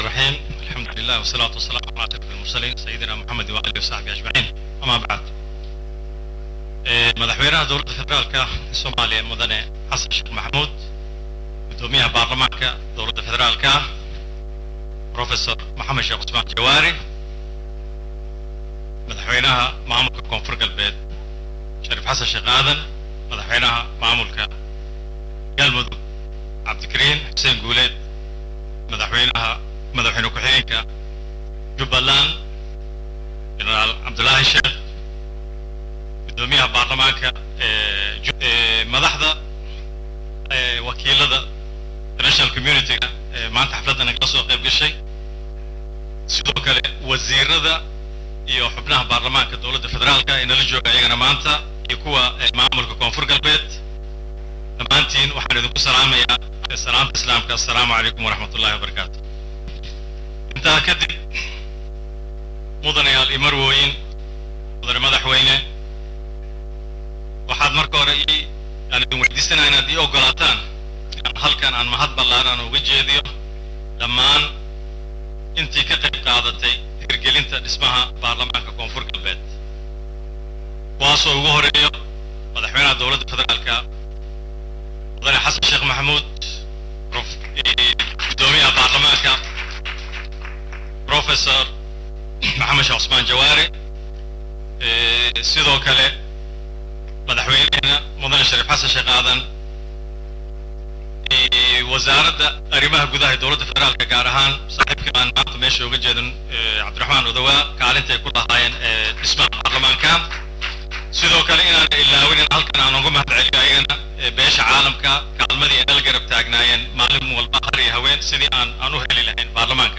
Dhageyso: Khudbada Gudoomiyaha Baarlamanka Koonfur Galbeed Soomaaliya
Baydhabo(INO)- Gudoomiyaha La caleema saarayey Ee baarlamanka Koonfur Galbeed Soomaaliya aya khudbadiisa waxa uu ugu hadley, waxybaaha uu qaban doona iyo weliba isagoona Gacan weystey Gudoonka baarlamanak Soomaaliya